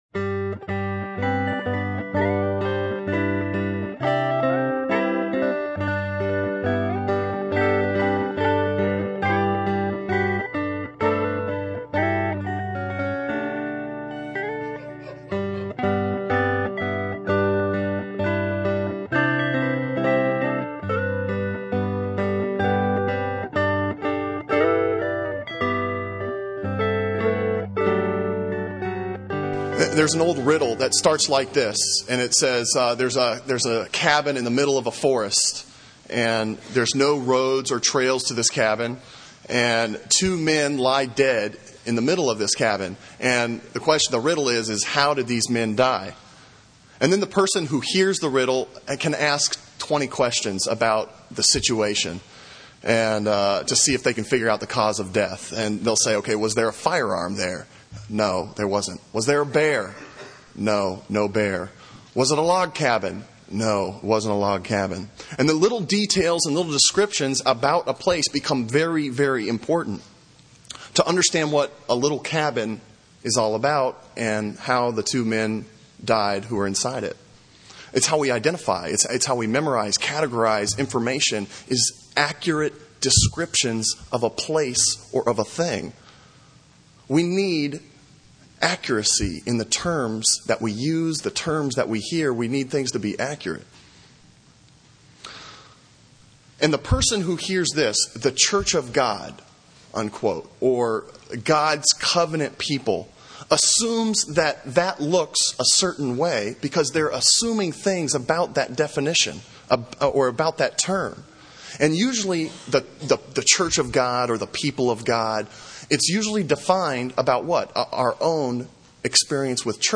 Sermon on Isaiah 56:1-8 from April 15